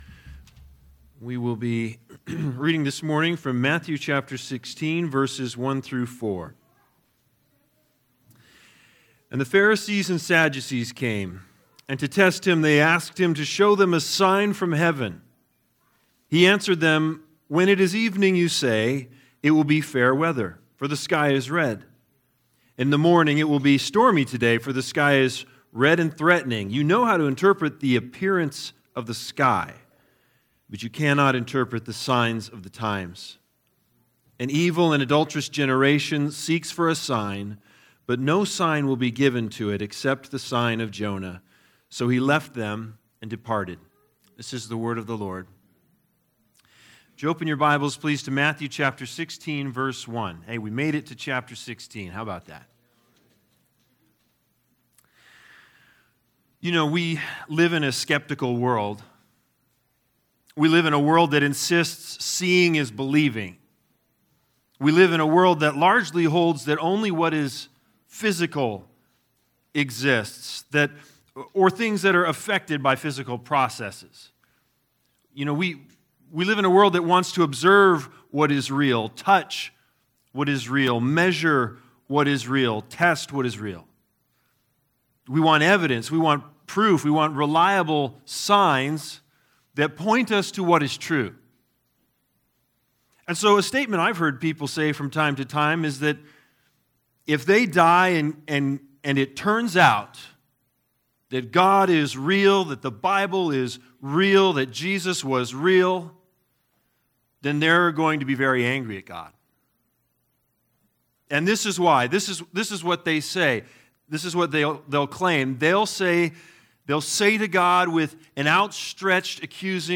Matthew 16:1-4 Service Type: Sunday Sermons BIG IDEA